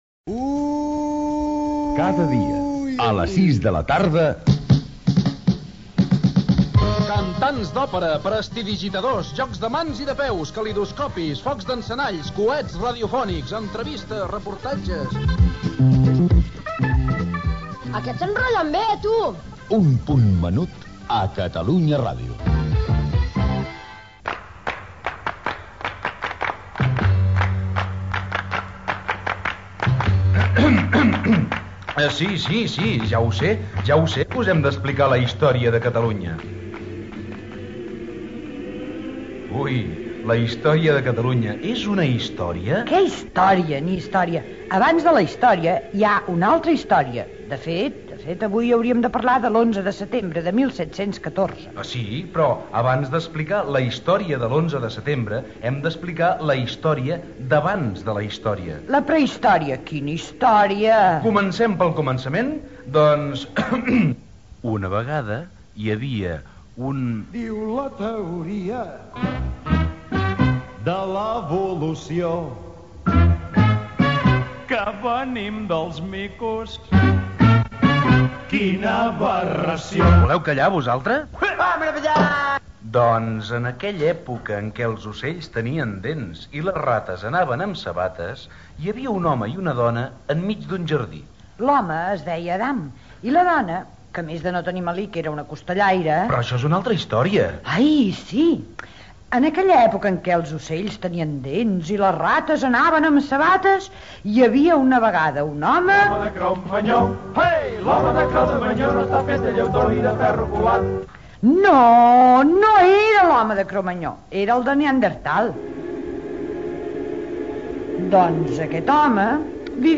Careta del programa i espai dedicat a la història de Catalunya, època prehistòrica.
Infantil-juvenil